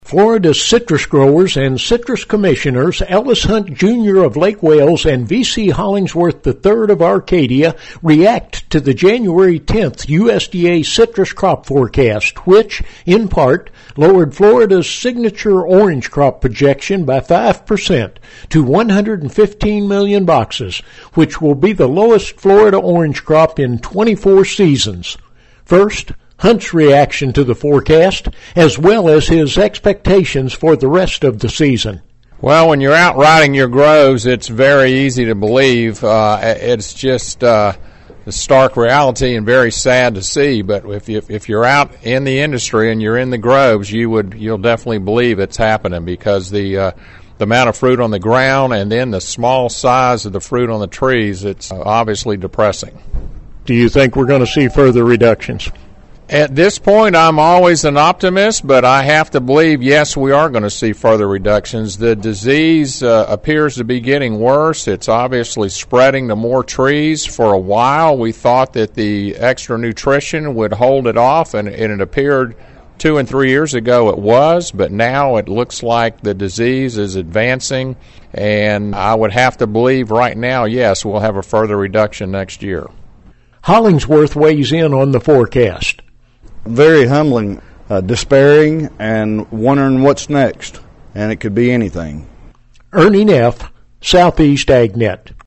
Ellis Hunt Jr. of Lake Wales and V.C. Hollingsworth III of Arcadia, both citrus growers and Florida citrus commissioners, react to large reductions in the January 10 citrus crop forecast, and look at the future.